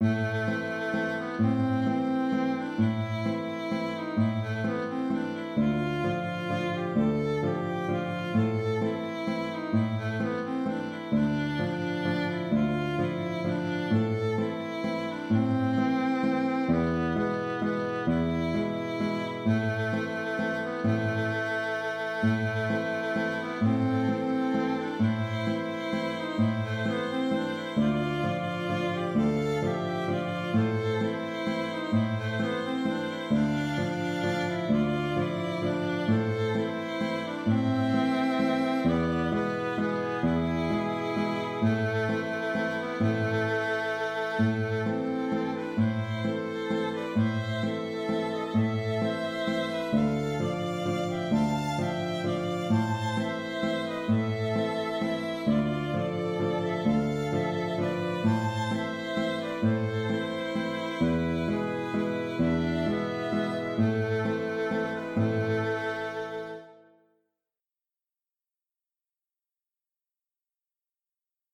Le contrechant peut prendre alors un peu de liberté, tout en essayant de garder l’esprit d’origine. Il y a deux contrechants, l’un très simple ; l’autre pouvant servir de variation.
Auteur : Chant traditionnel russe du 19e siècle.